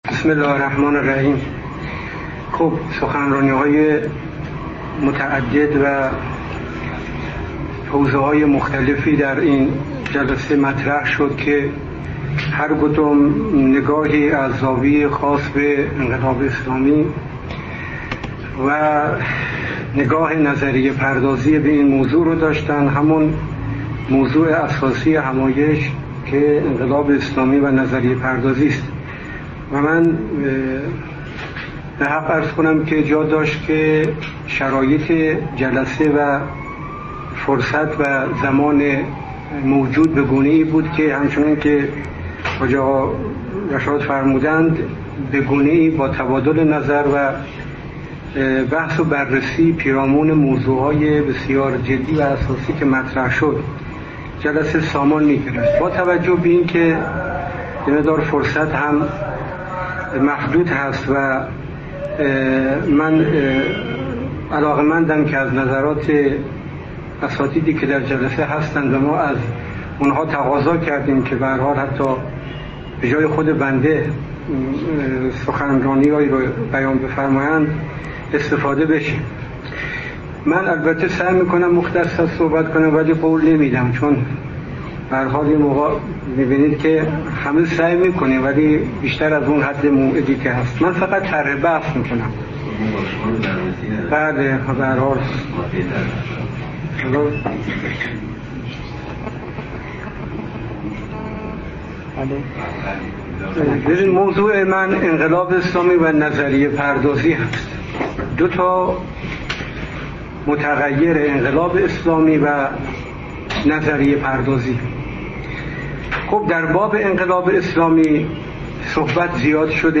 در همایش انقلاب اسلامی و نظریه پردازی در پژوهشگاه فرهنگ و اندیشه اسلامی
سخنرانی